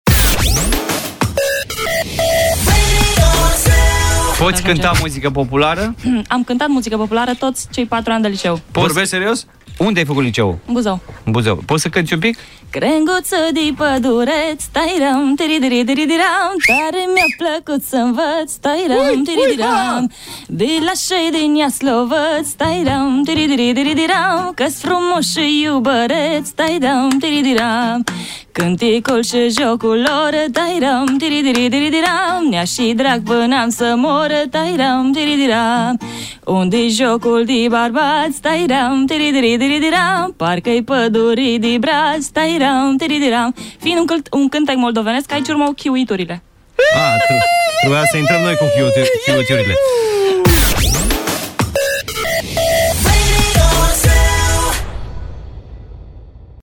Ellie White canta live la Radio ZU
Ceva muzica populara
Ellie_White_canta_muzica_populara.mp3